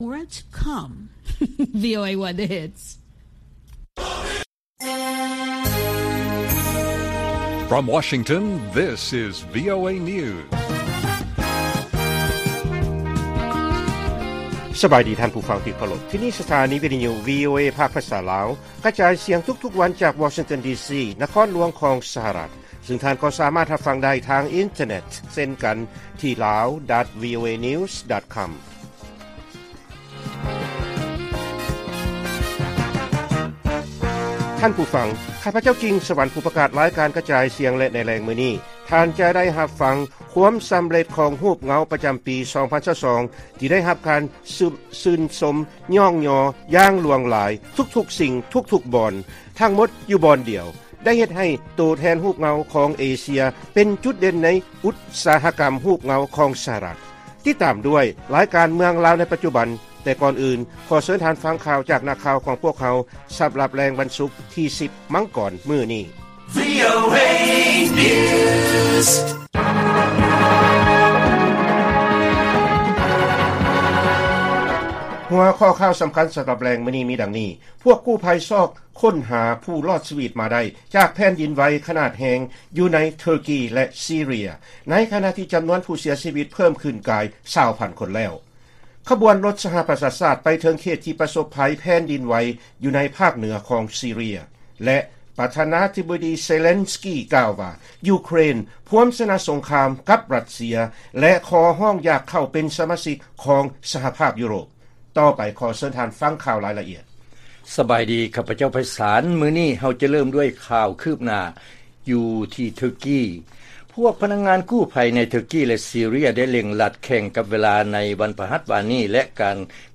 ລາຍການກະຈາຍສຽງຂອງວີໂອເອ ລາວ: ພວກກູ້ໄພຊອກຄົ້ນຫາຜູ້ລອດຊີວິດມາໄດ້ ຈາກແຜ່ນດິນໄຫວຂະໜາດແຮງ ຢູ່ໃນເທີກີ ແລະຊີເຣຍ ເຊິ່ງຜູ້ເສຍຊີວິດກາຍ 20,000 ຄົນແລ້ວ.